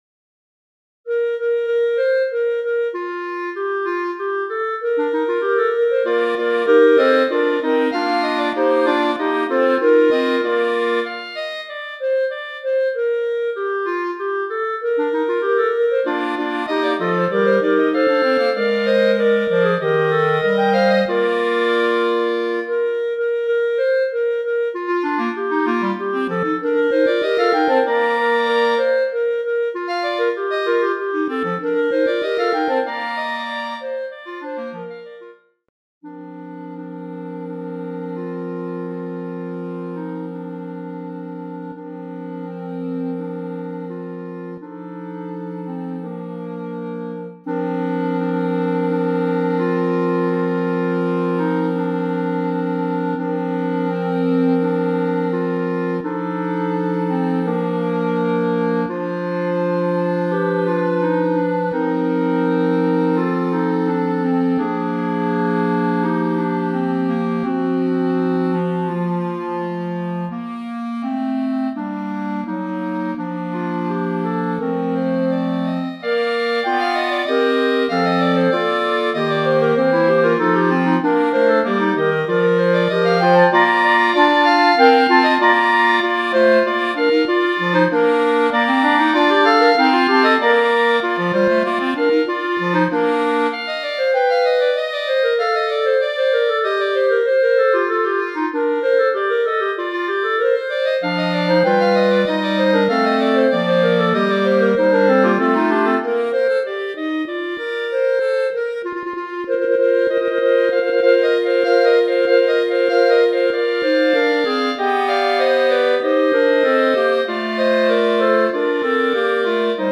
Voicing: Clarinet Quartet